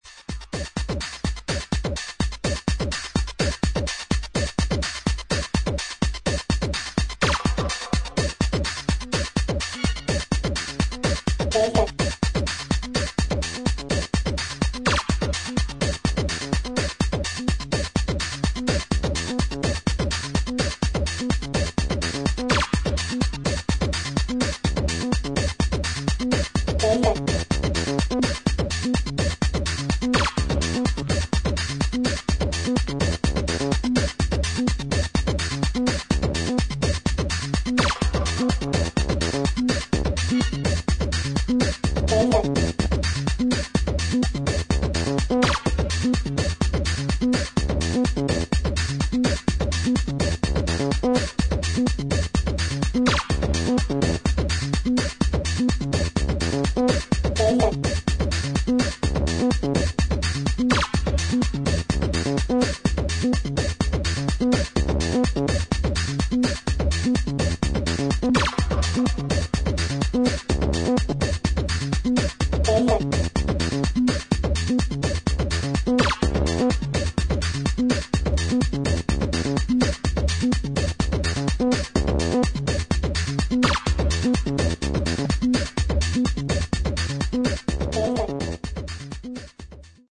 ハードウェアサウンドの面白さを堪能できる一枚です。